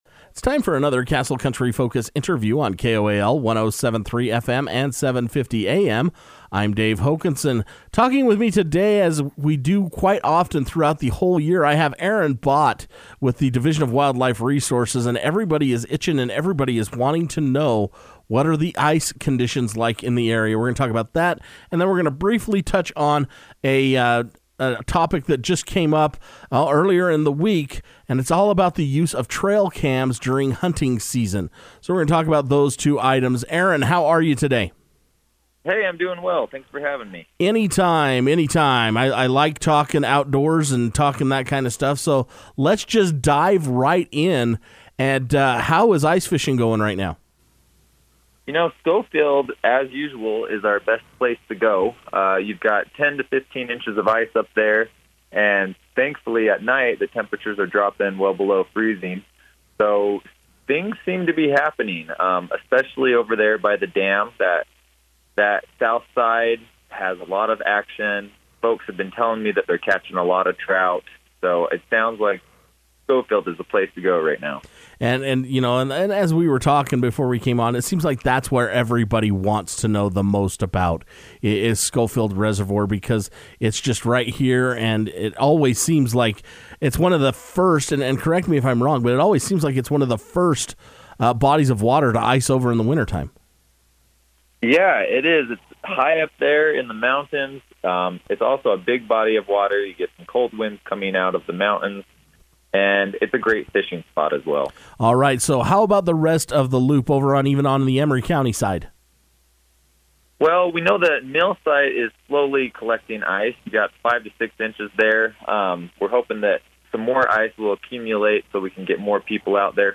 The Division of Wildlife Resources takes time each month to speak with Castle Country Radio to discuss various topics.